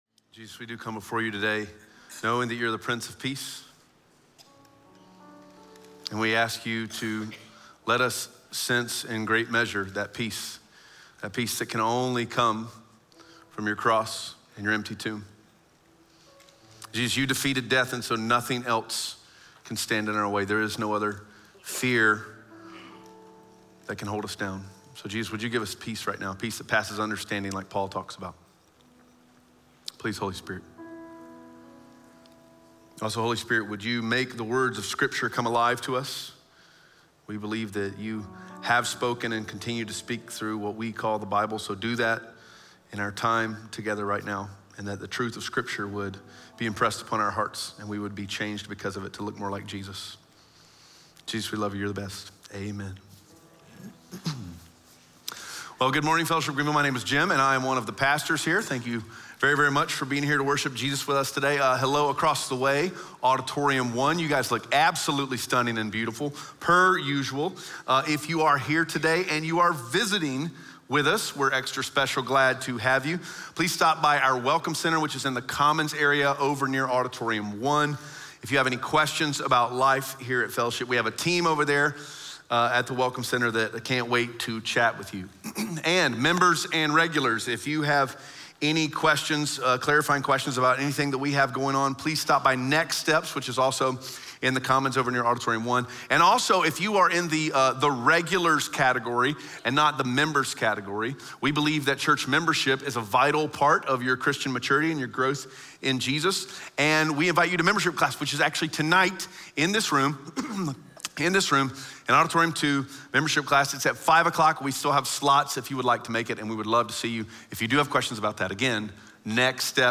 Galatians 2:11-14 Audio Sermon Notes (PDF) Ask a Question Scripture: Galatians 2:11-14 SERMON SUMMARY Hypocrisy is more like cancer than acne.